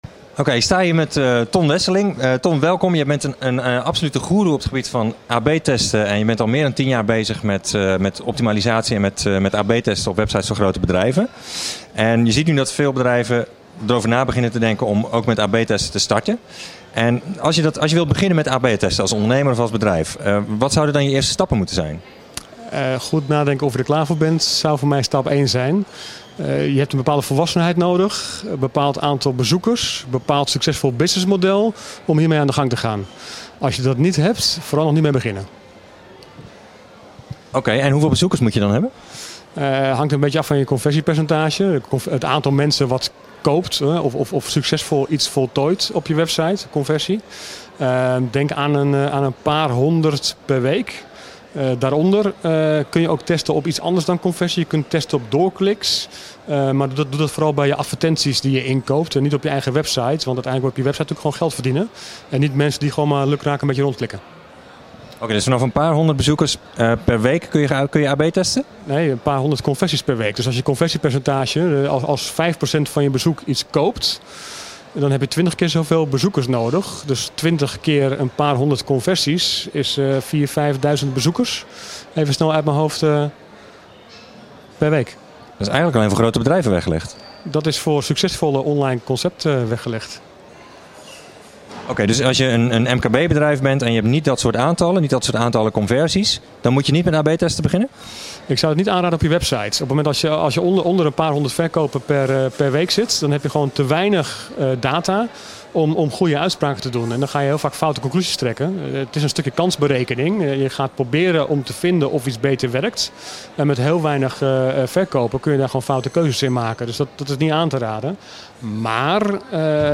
geïnterviewd